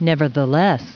Prononciation du mot nevertheless en anglais (fichier audio)
Prononciation du mot : nevertheless